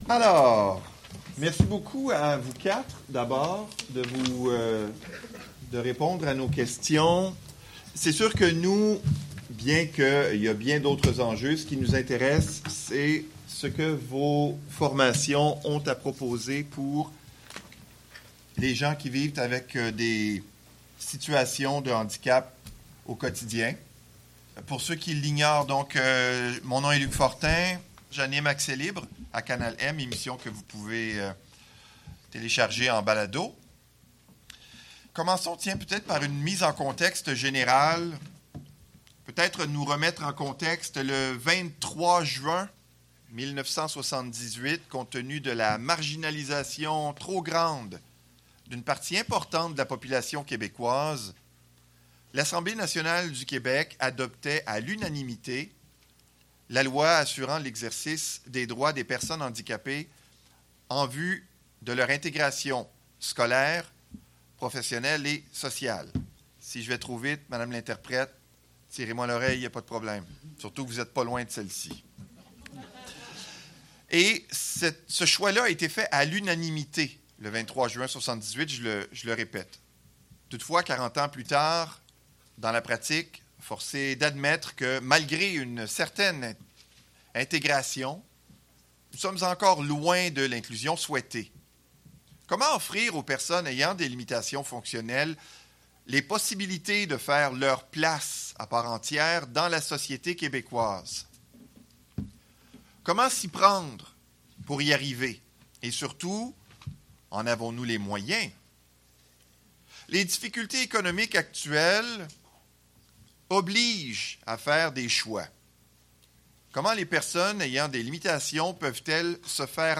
La Confédération des organismes de personnes handicapées du Québec (COPHAN) a organisé une soirée pré-électorale le 10 septembre dernier, à Montréal. L’événement a permis à des représentants de plusieurs organismes de découvrir les idées des quatre principaux partis qui briguent les suffrages dans la présente campagne électorale.